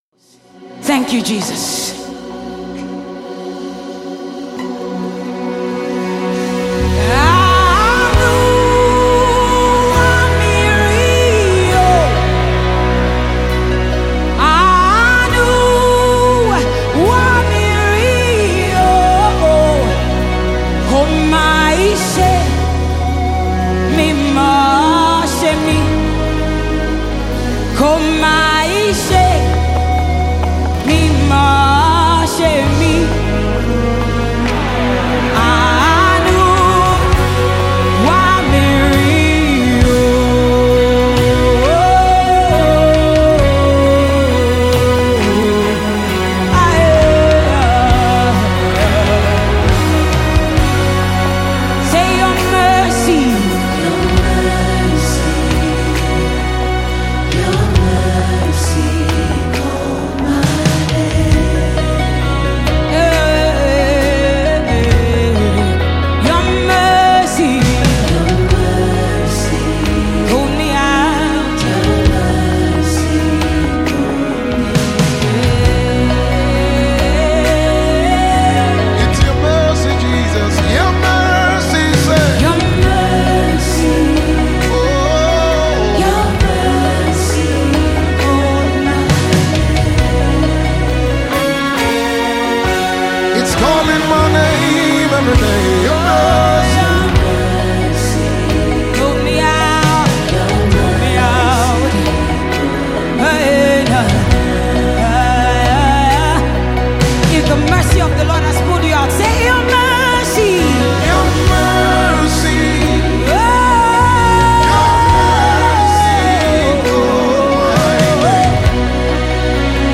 worship track